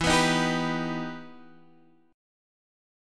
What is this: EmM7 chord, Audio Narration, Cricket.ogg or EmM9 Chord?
EmM7 chord